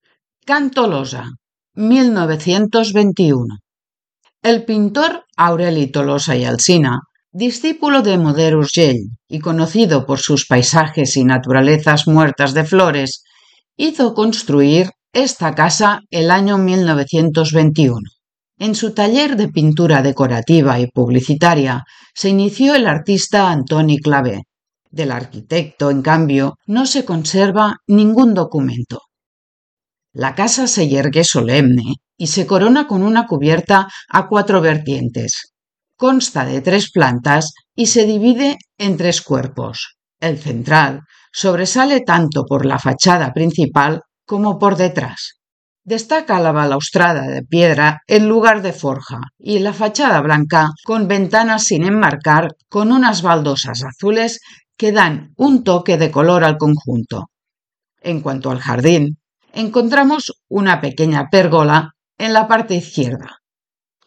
• RUTA MODERNISTA AUDIOGUIADA